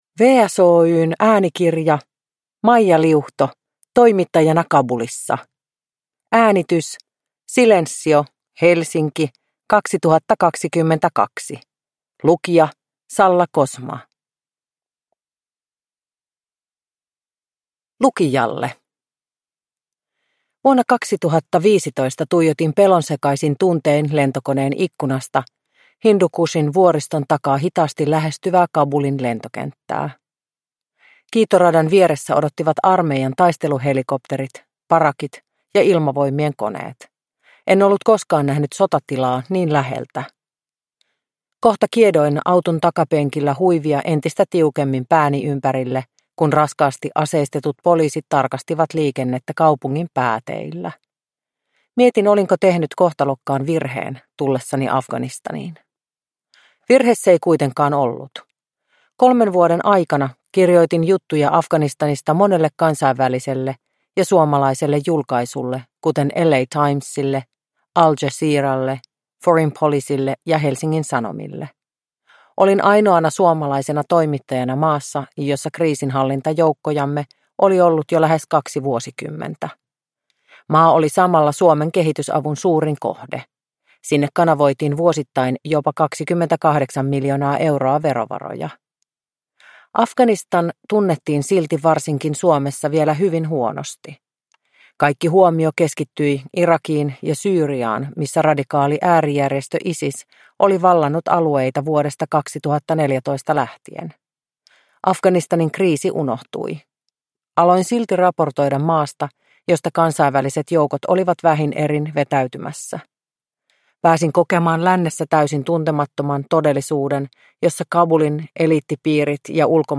Toimittajana Kabulissa – Ljudbok – Laddas ner